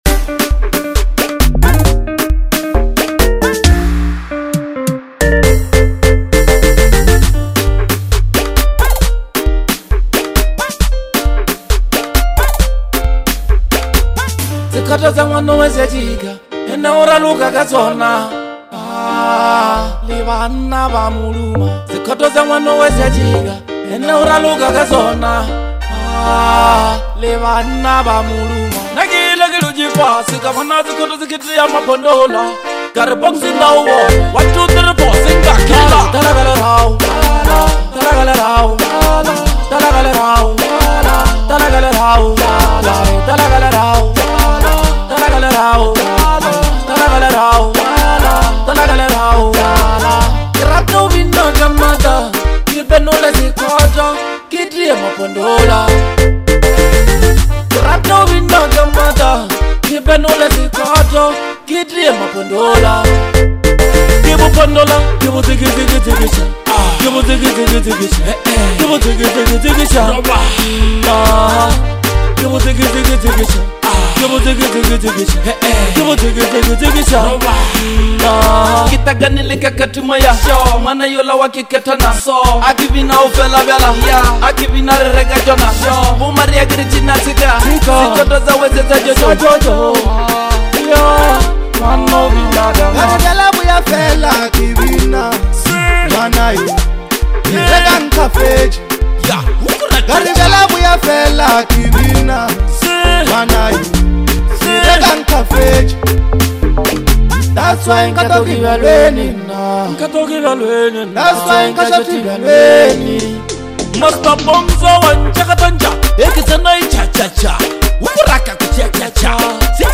is a powerful,high energy anthem